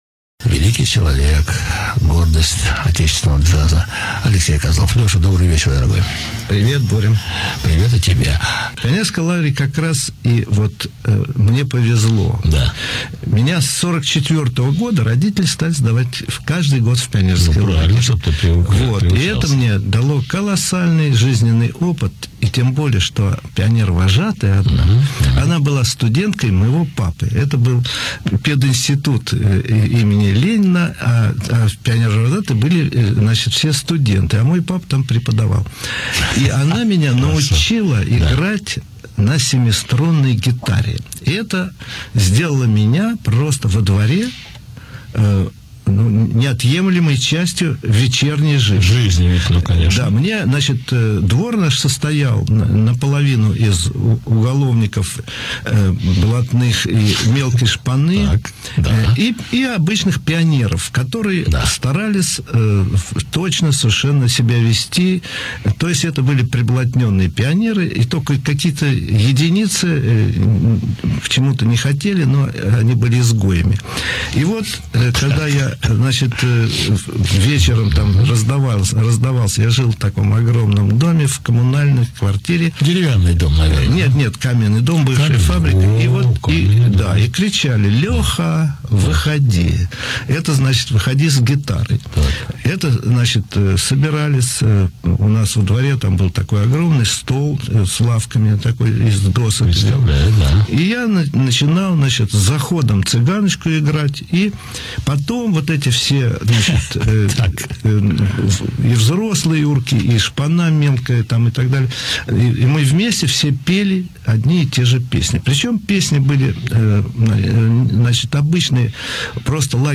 Легендарный джазмен АЛЕКСЕЙ С. КОЗЛОВ показывает дворовые песни своего детства.